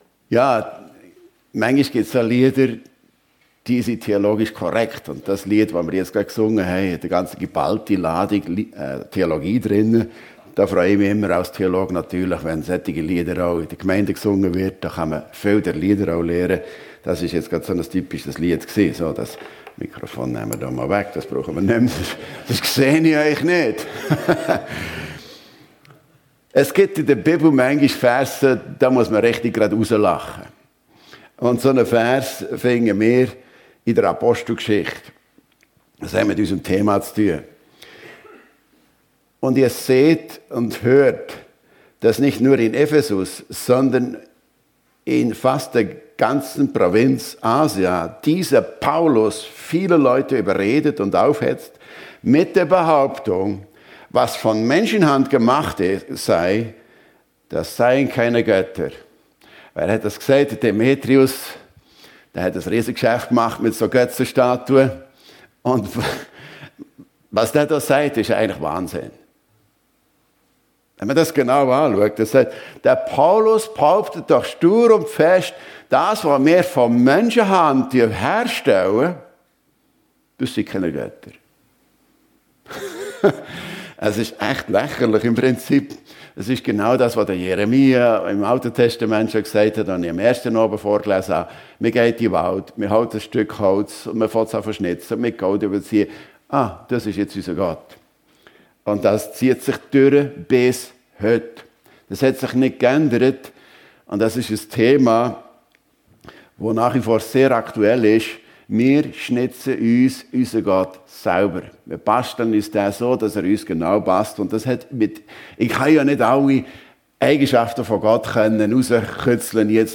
Bibeltage - Was tut Gott? Er weiss alles, kann alles und liebt alle? ~ FEG Sumiswald - Predigten Podcast
Bibeltage 2024